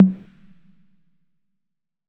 Tom_C1.wav